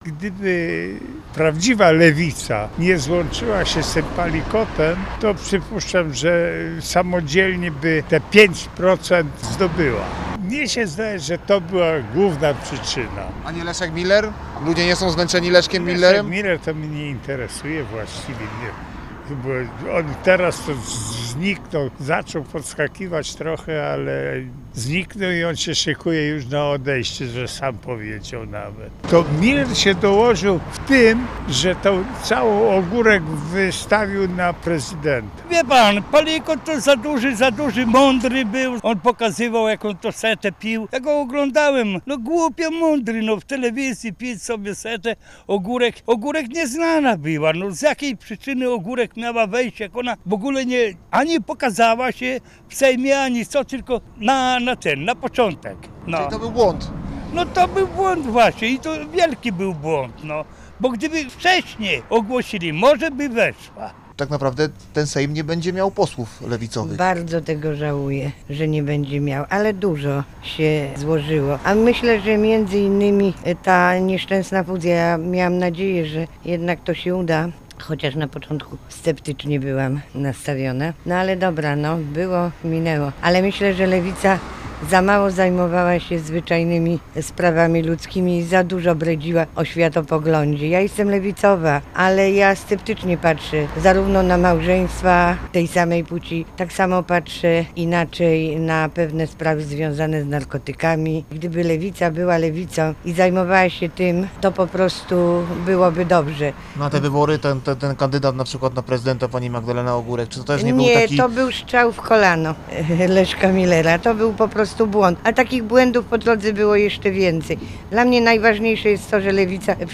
Posłuchaj, co ludzie sądzą o porażce partii lewicowych: Nazwa Plik Autor Sonda audio (m4a) audio (oga) ZDJĘCIA, NAGRANIA VIDEO, WIĘCEJ INFORMACJI Z ŁODZI I REGIONU ZNAJDZIESZ W DZIALE “WIADOMOŚCI”.